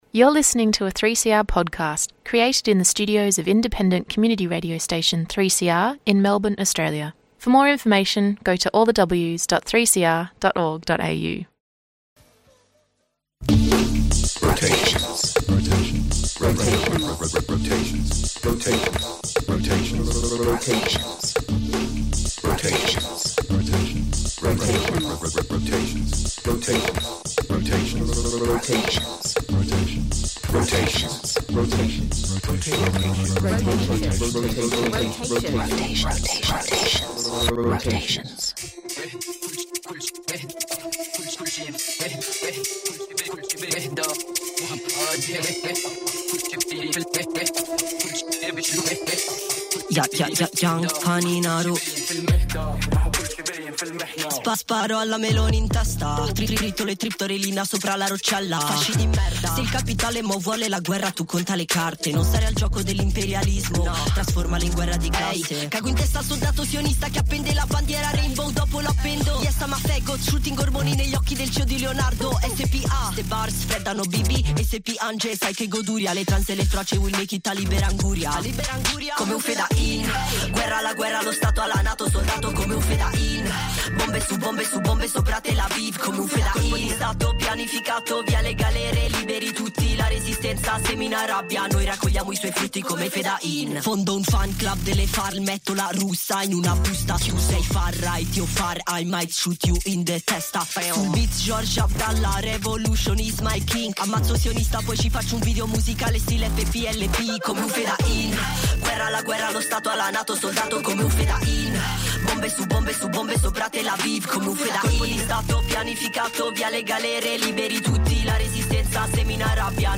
7-piece soul-funk outfit
playing live to air from Studio 3!